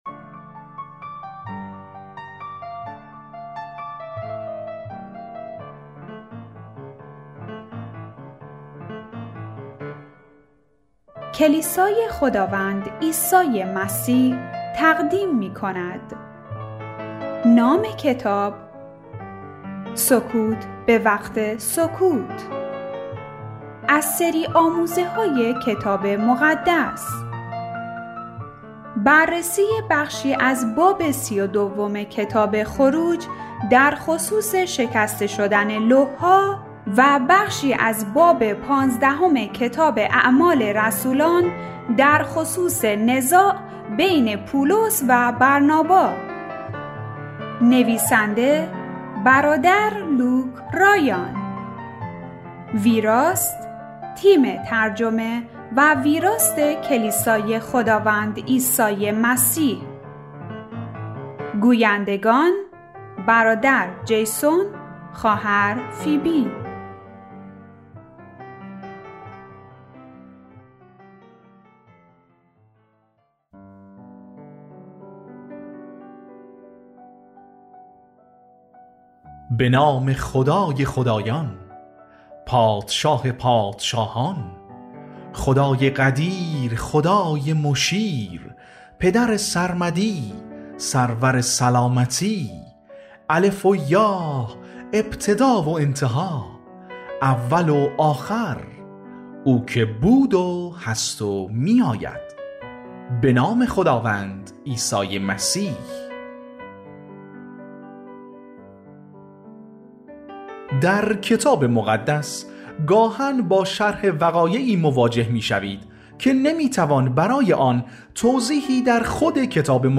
پخش آنلاین و دانلود کتاب صوتی سکوت به وقت سکوت